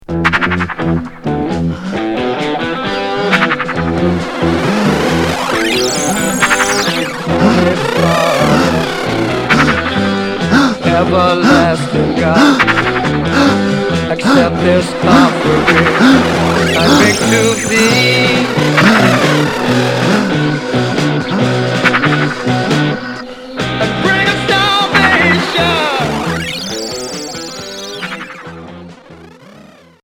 Expérimental